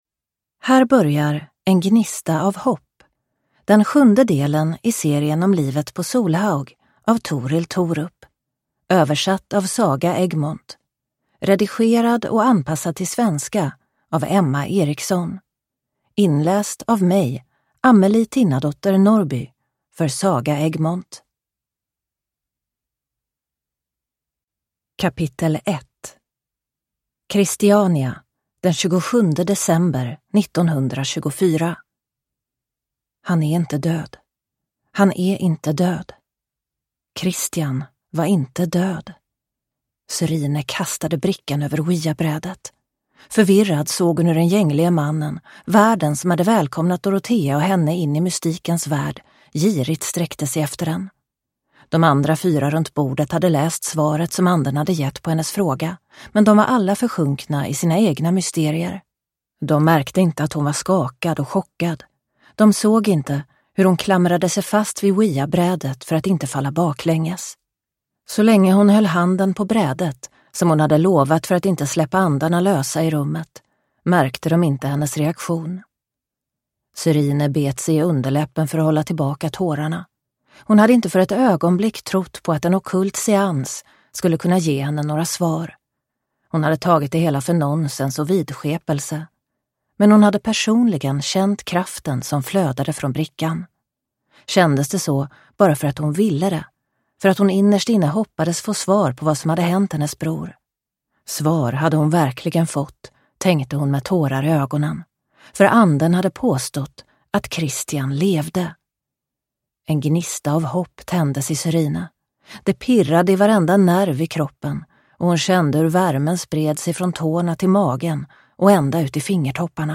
En gnista av hopp (ljudbok) av Torill Thorup